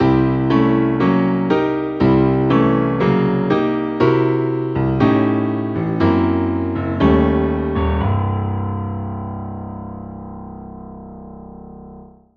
Audio: Jazz Symmetrical Scales Octave chord variations transposed
Jazz-Symmetrical-Scales-Octa-Mel-chord-variations.mp3